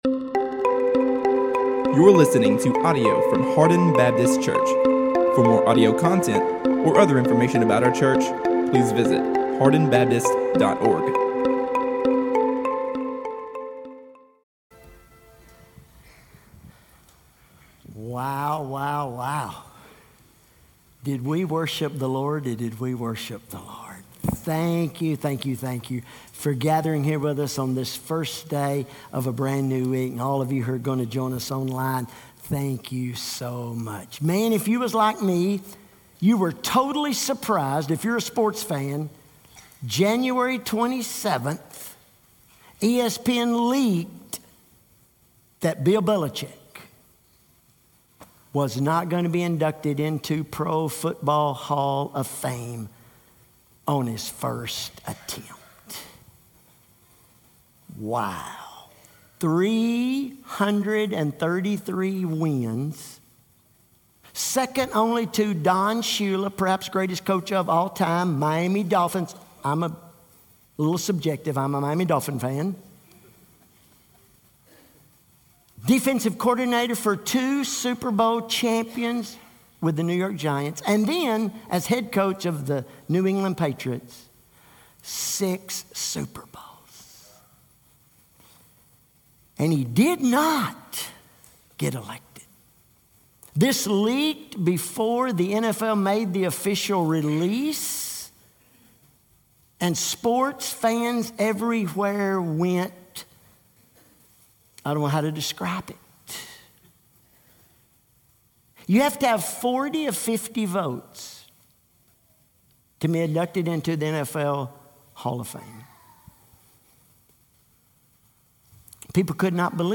All Sermons – Hardin Baptist Church